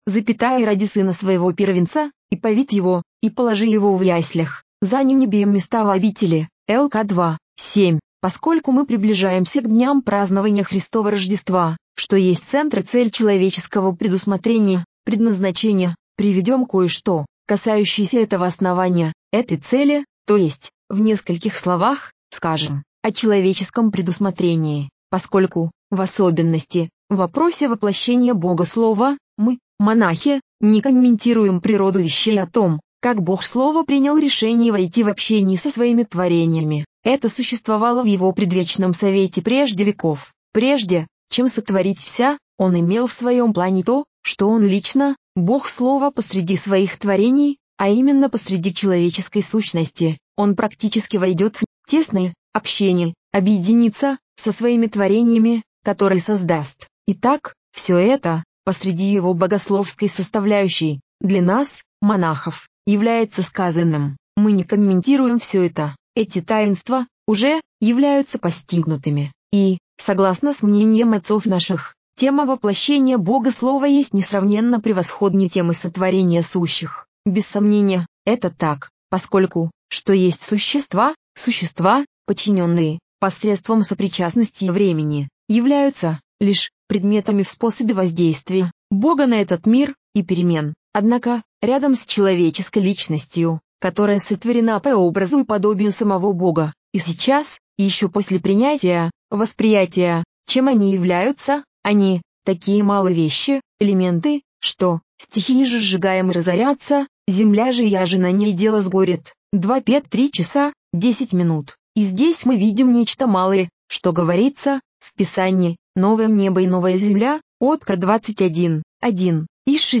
Рождественское слово старца Иосифа Ватопедского к братии. Старец Иосиф задается вопросом о том, почему Боговоплощние произошло именно таким способом, и к чему были нужны Богу все эти трудности и бесчестие, и какое это имеет связь с монашеской жизнью.